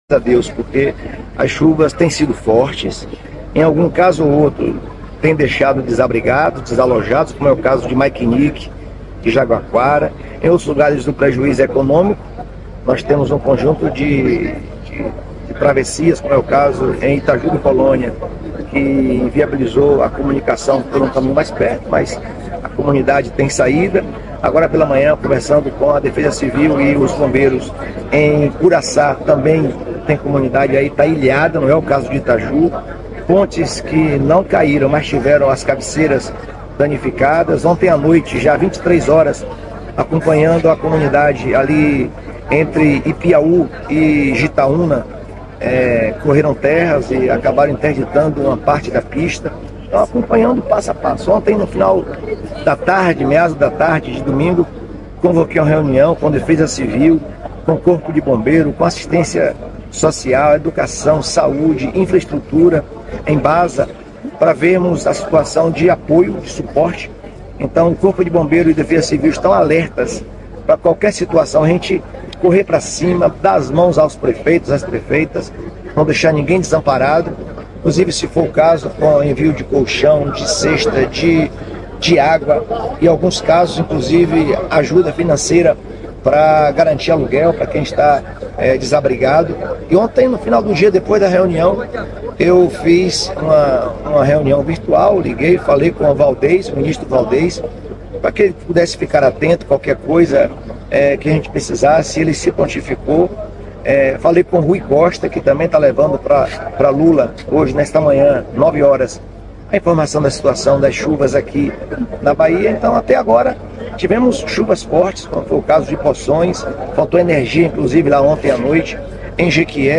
O governador da Bahia, Jerônimo Rodrigues, detalhou na manhã desta segunda-feira (13) as ações do governo para socorrer os municípios atingidos pelas fortes chuvas que castigaram o estado no final da semana. O pronunciamento ocorreu durante o lançamento de um pacote de obras para novas escolas na capital, Salvador.